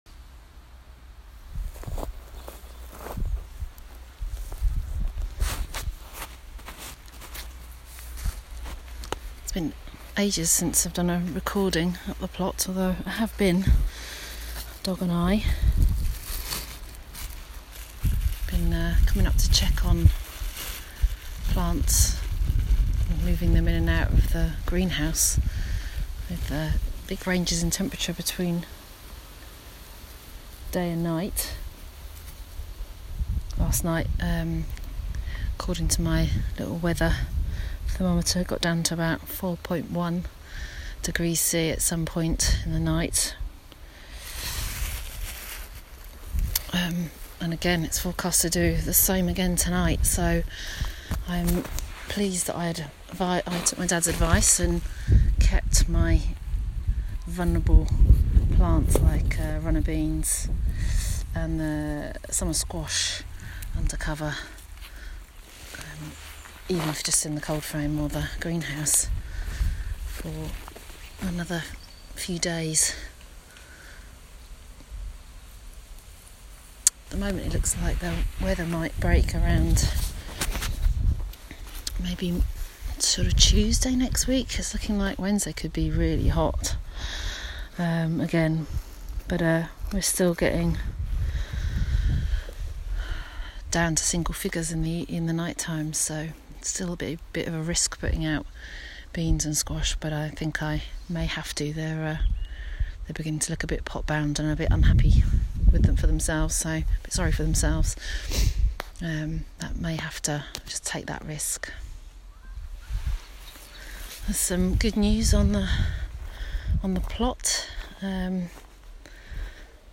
Hear from one woman and her dog as she explores the organic gardening world in short sound bites.
*Please note, outside recording carried out in accordance with national guidance as part of daily exercise with respect to social distancing*